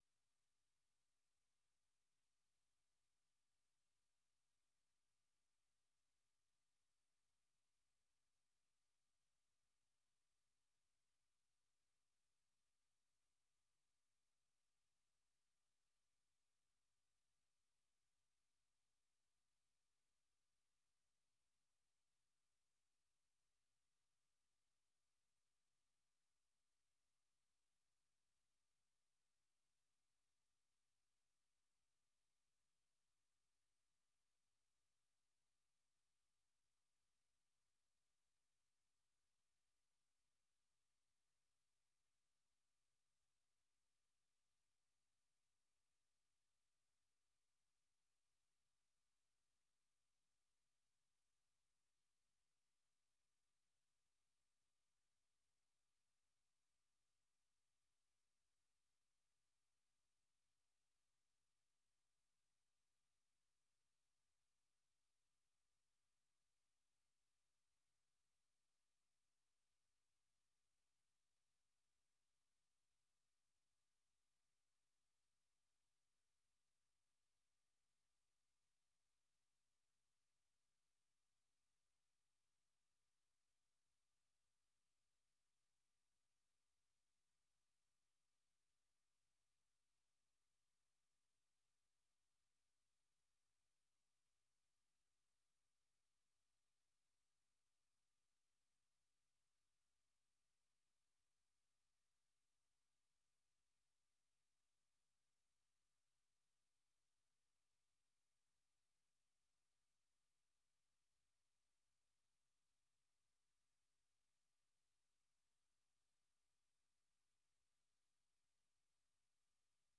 Learning English uses a limited vocabulary and are read at a slower pace than VOA's other English broadcasts.